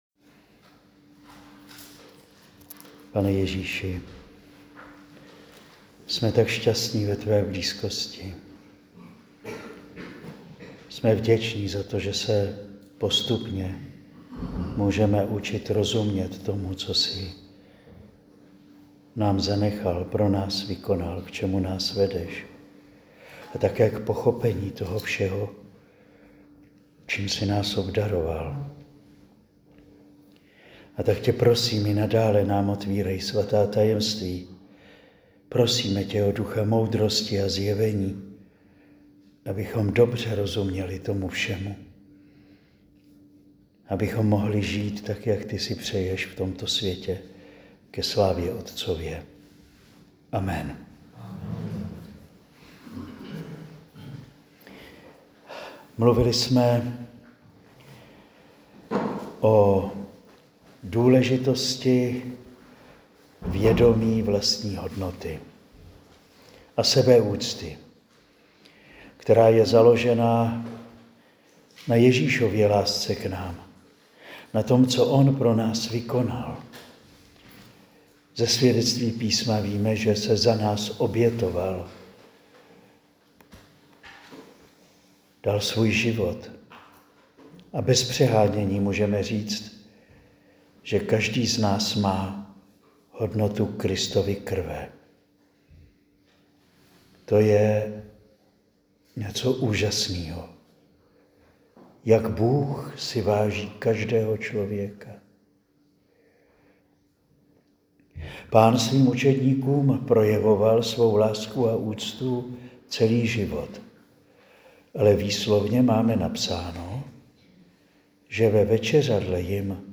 Nyní si můžete poslechnout šestou promluvu.
Promluvy zazněly na exerciciích pro řeckokatolické kněze a jejich manželky v Juskovej Voli na Slovensku v listopadu 2025.